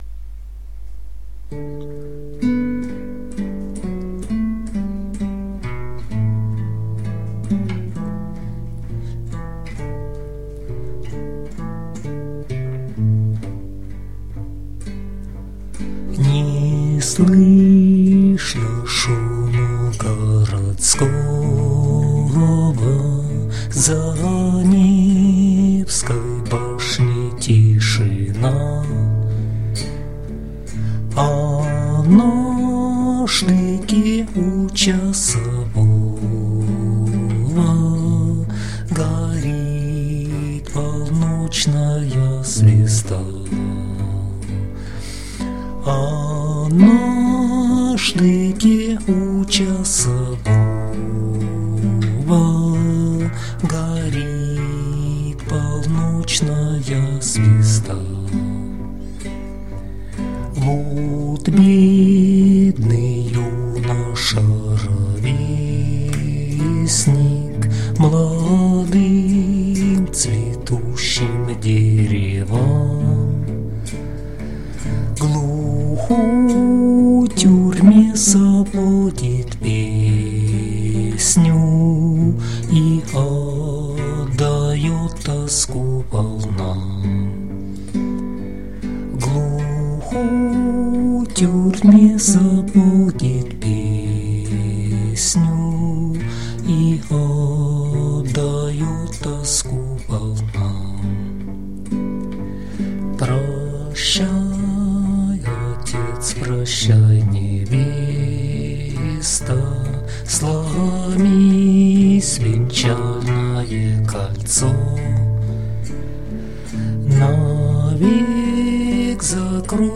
../icons/nenaglya.jpg   Русская народная песня